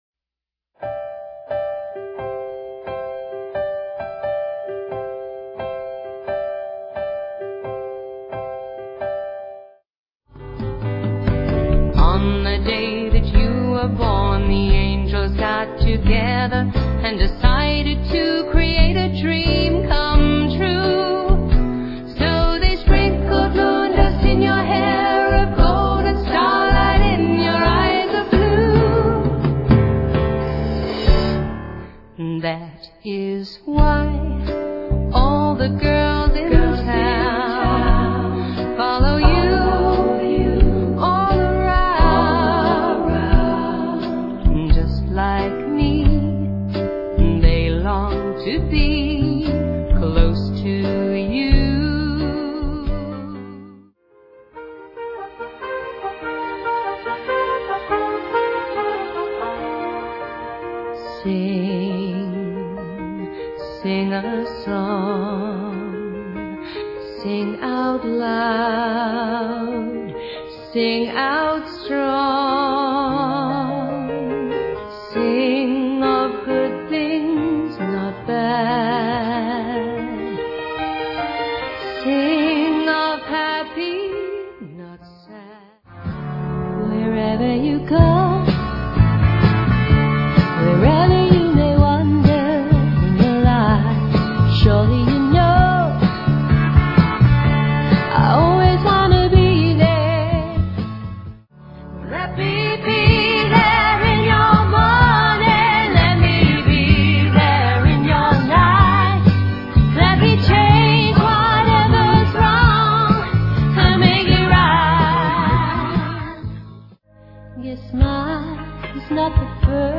tribute band